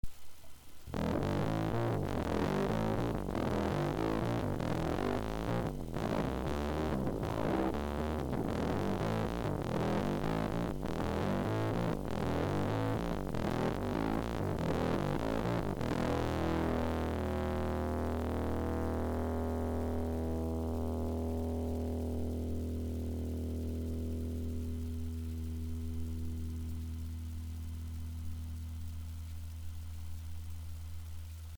『Ibanez RD400』に『EBS Multi Driver』をセット。
FLATモードでDRIVEは12時方向。
ピックアップはリアハムのみ。ピッキングはブリッジ付近。
イコライザは普通の音を出してるときと同じ。